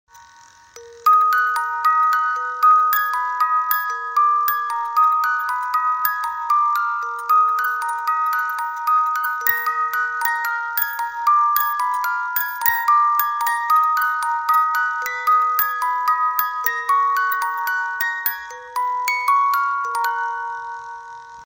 Clásicos , Romántico